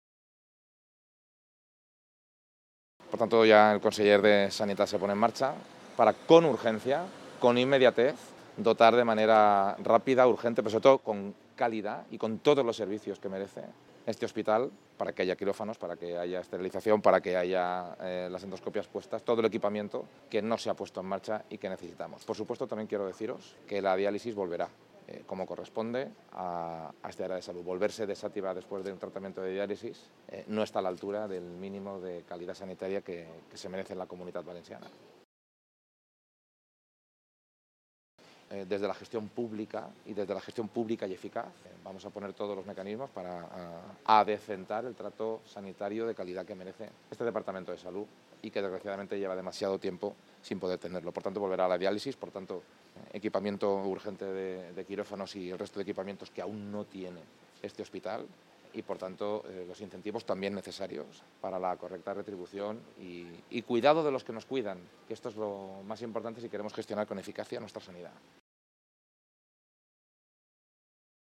En las declaraciones que ha realizado a los medios de comunicación, Carlos Mazón ha informado que ha podido constatar tras esta vista la falta de medios y dotación que padece el Hospital de Ontinyent. donde ha comprobado la falta de quirófanos o de servicios esenciales como el de esterilización o pruebas endoscópicas.